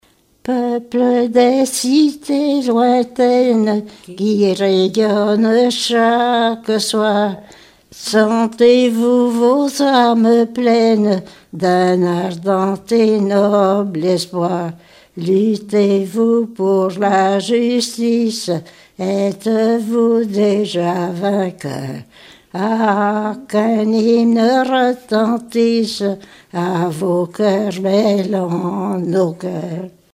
chansons d'écoles et populaires
Pièce musicale inédite